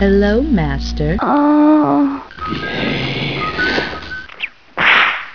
Sound Effects a mix of Public Domain